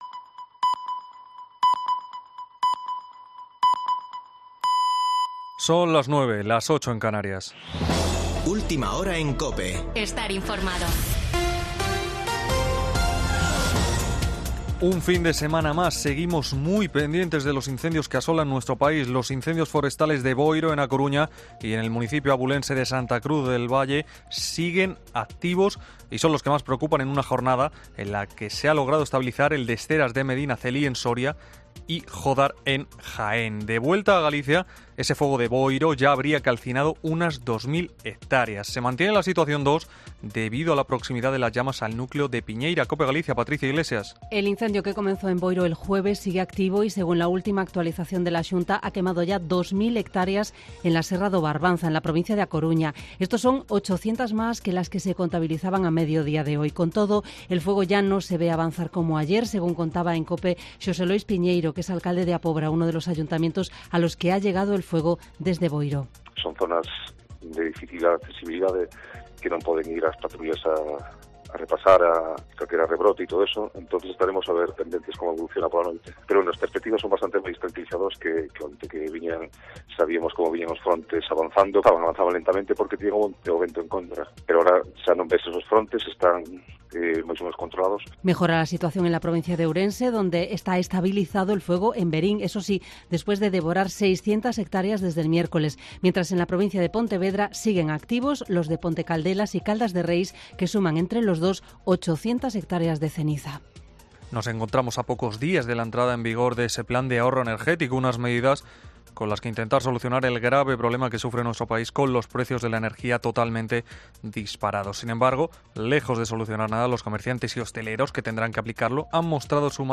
Boletín de noticias de COPE del 6 de agosto de 2022 a las 21.00 horas